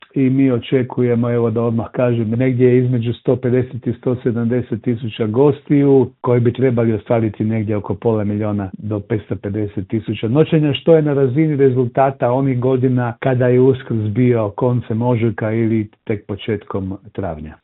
u telefonskom Intervjuu Media servisa naglašava da možemo biti optimistični, ali oprezni.